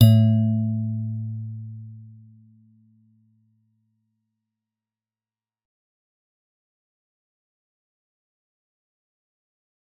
G_Musicbox-A2-f.wav